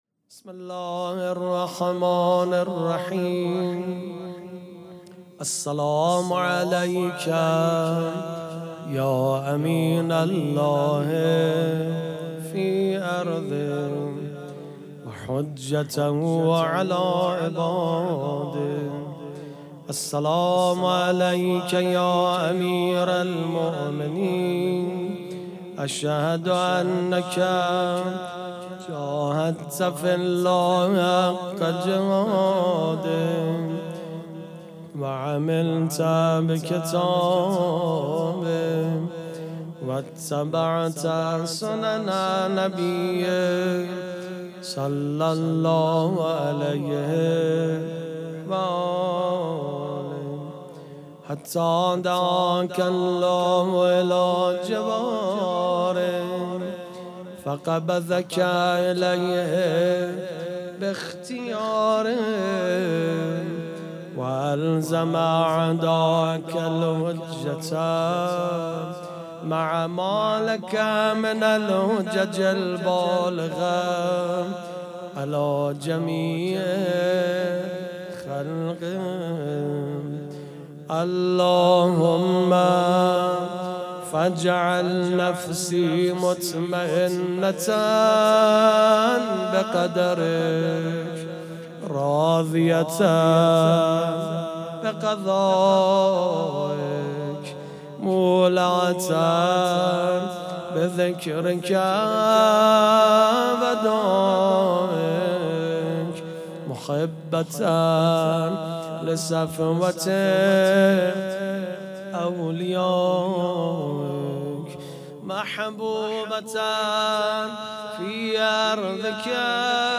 سبک اثــر پیش منبر
جشن عید غدیرخم